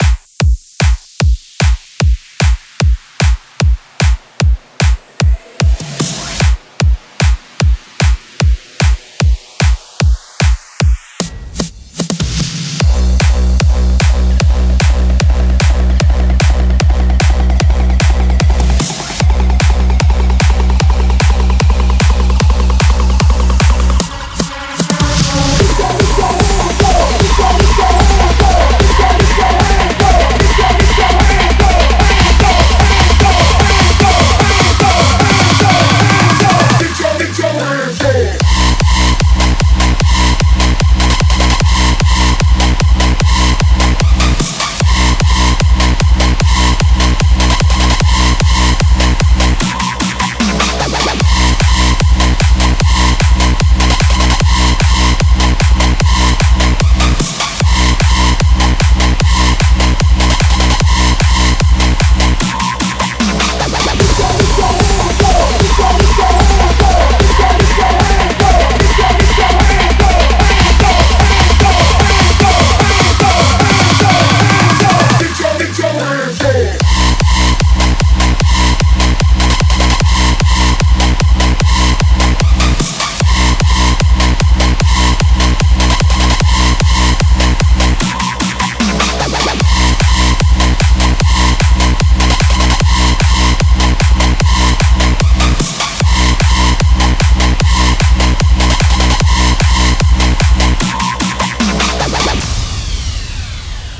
试听文件为低音质